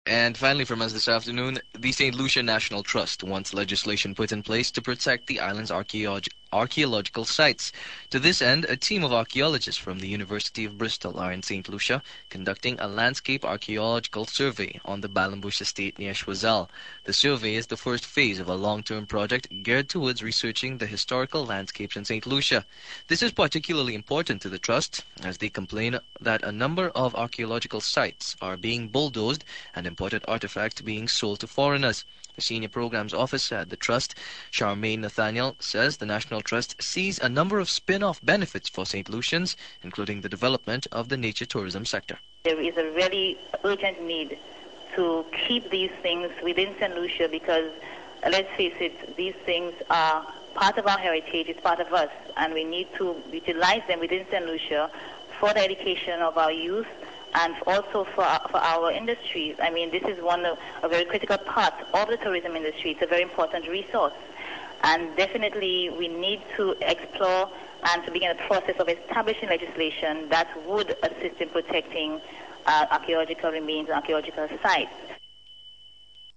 It shares characteristics of acrolectal Jamaican English (see relevant entry) such as r-lessness, lack of diphthongisation, lack of distinctive vowel length and syllable-timing.
St_Lucia_Acrolectal.wav